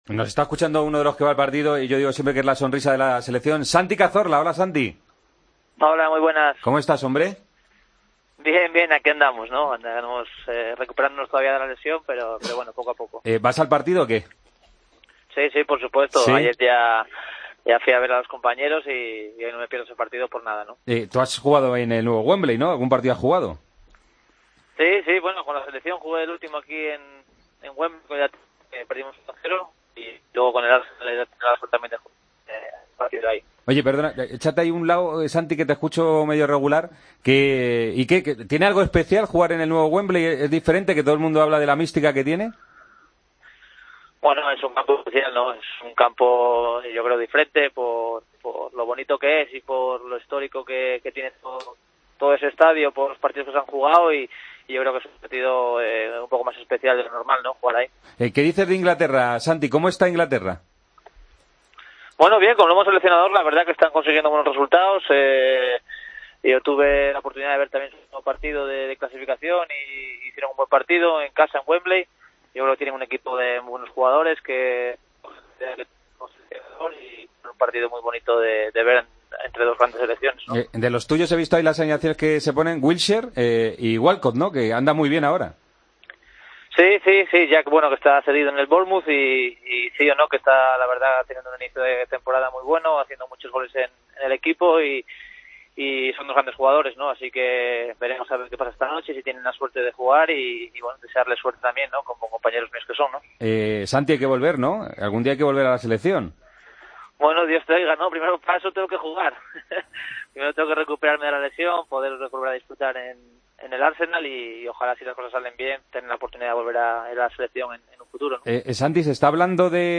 Hablamos con el jugador del Arsenal, horas antes del encuentro entre España e Inglaterra: "Con la selección ya he jugado en Wembley y es un campo especial, diferente, por lo bonito que es y por la historia que tiene.